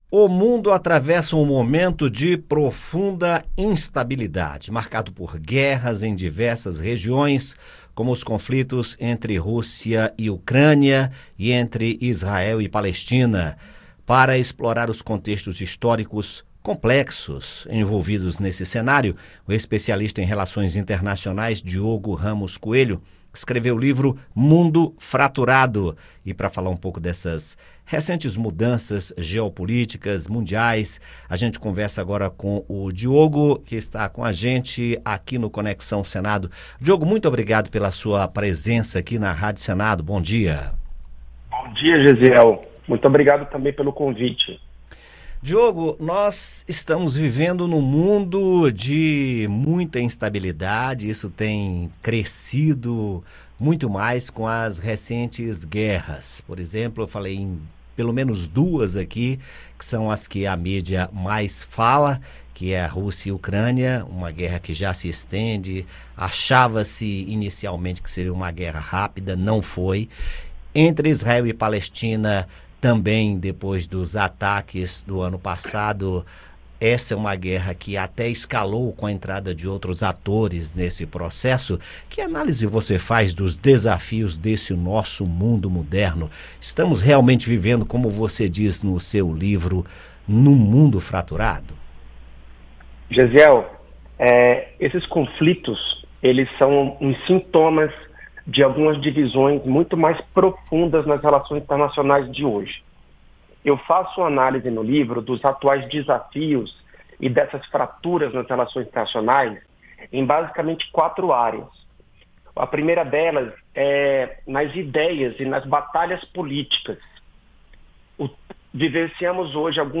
Especialista fala sobre mudanças na geopolítica mundial e novas configurações globais